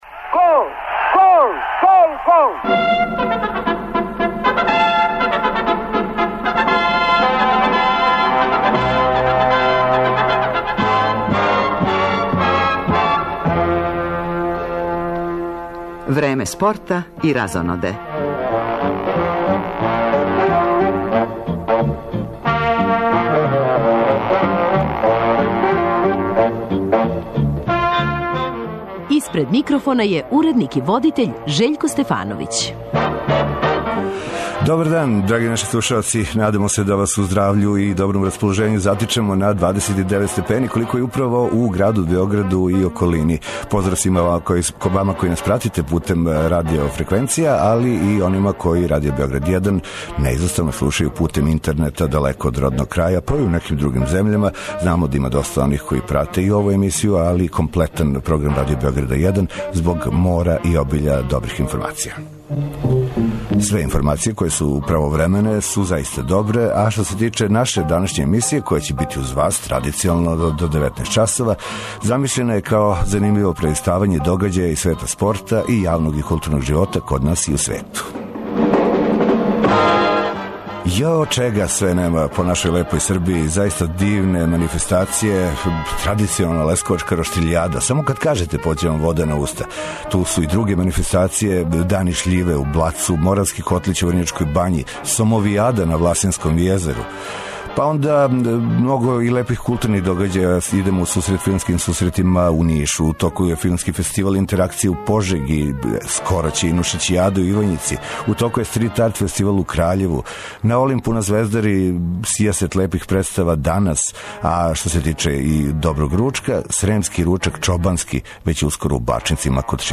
Гошће у студију су две младе обојкашице